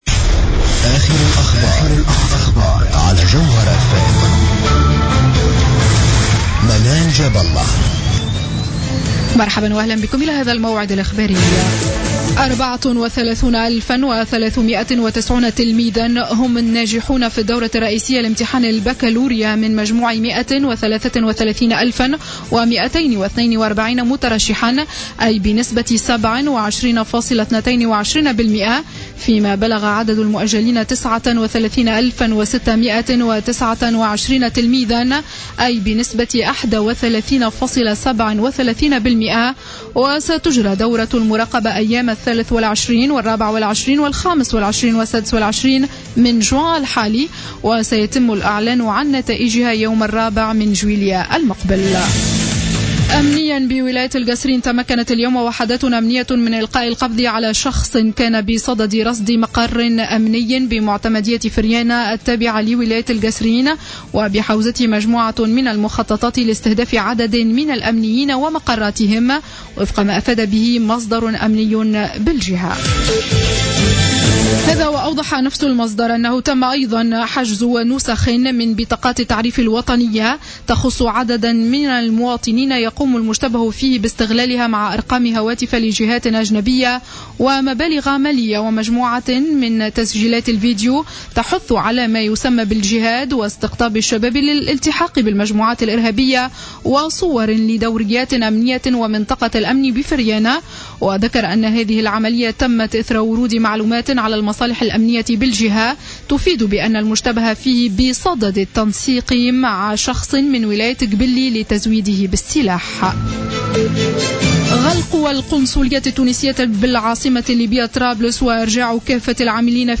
نشرة أخبار الساعة الخامسة مساء ليوم الجمعة 19 جوان 2015